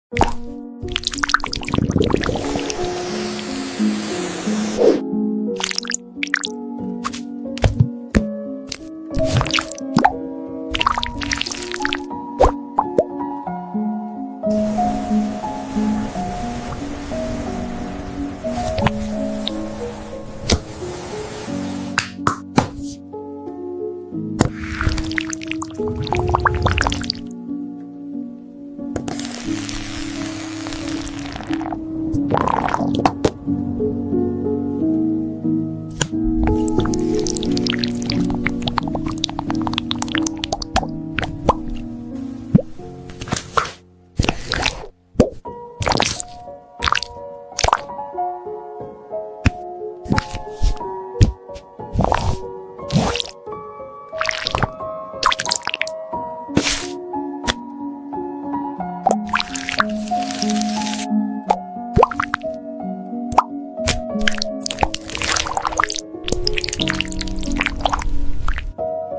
Tom And Jerry With ASMR Sound Effects Free Download